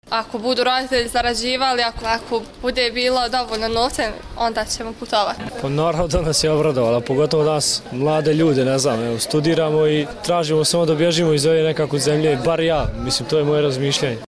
Anketa: Građani Banja Luke o ukidanju viza